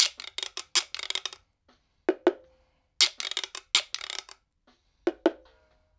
80-PERC1.wav